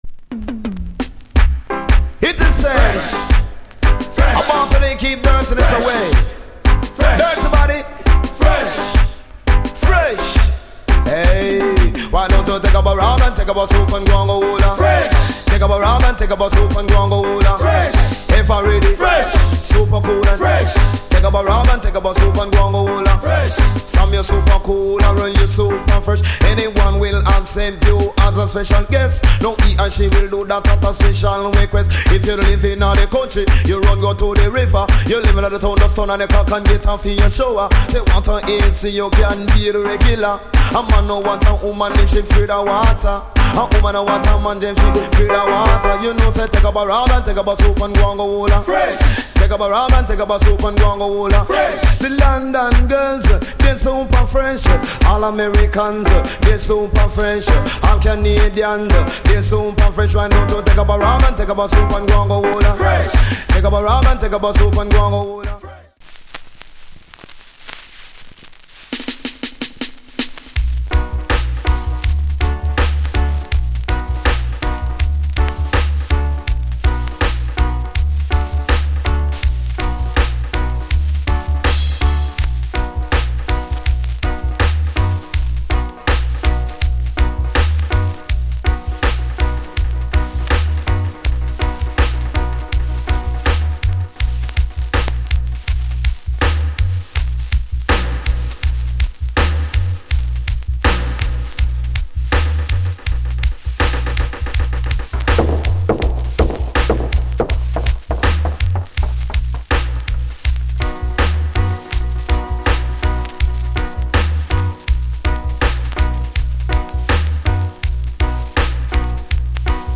* '87 Big Hit **フレッシュ!のかけ声で大人気の一曲